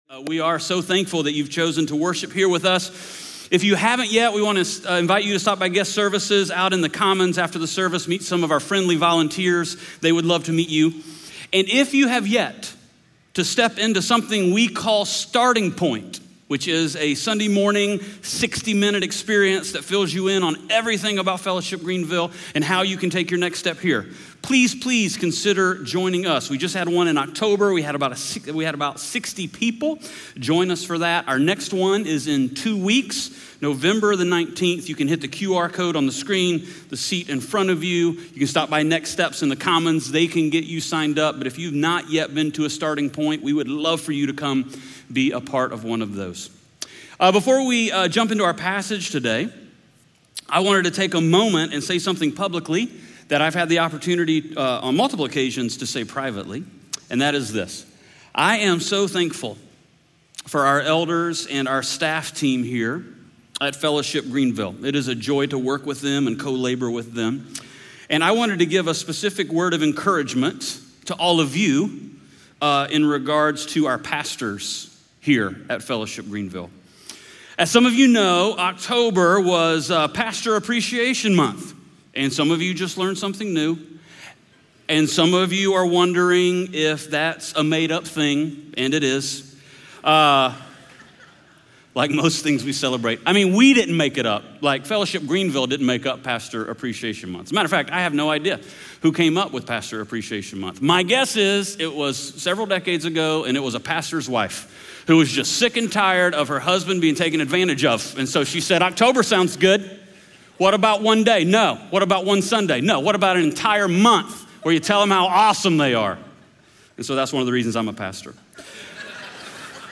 Luke 16:1-15 Audio Sermon Notes (PDF) Ask a Question Scripture: Luke 16:1-15 SERMON SUMMARY Jesus talked a good bit about our money and our stuff.